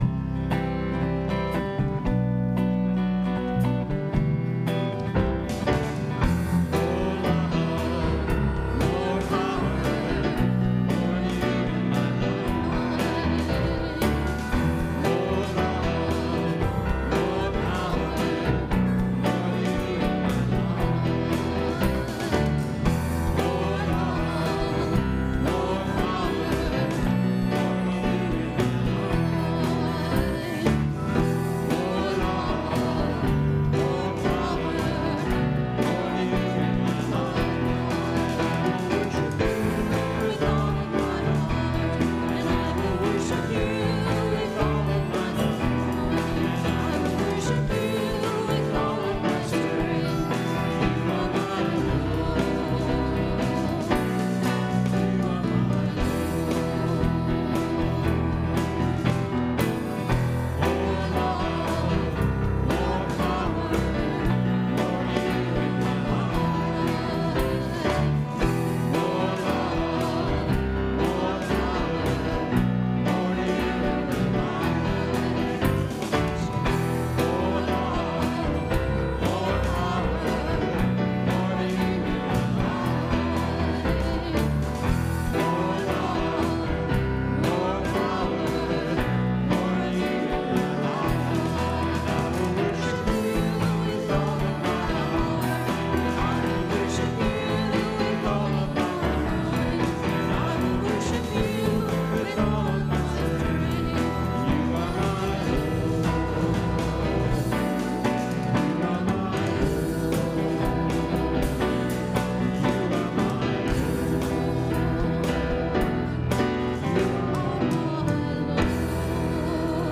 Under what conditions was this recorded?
Podcast from Christ Church Cathedral Fredericton